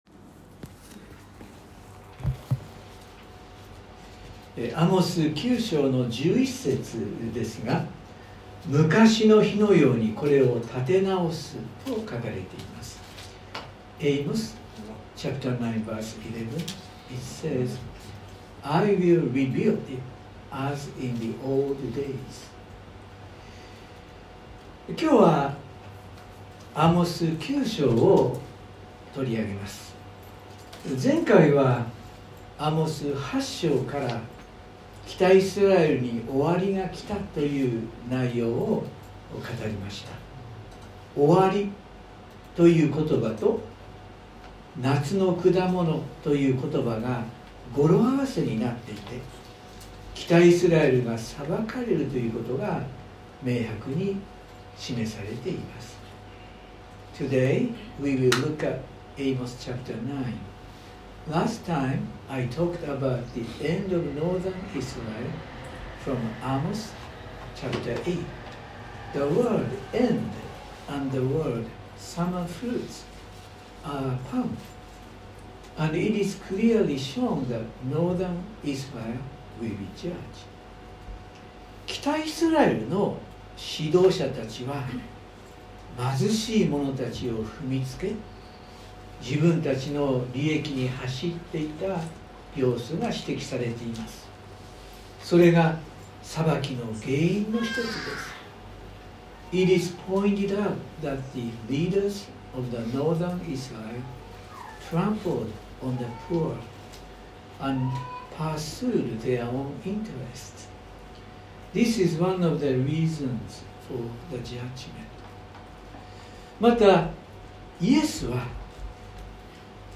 （日曜礼拝録音） 【iPhoneで聞けない方はiOSのアップデートをして下さい】 今日は、アモス書9章を取り上げます。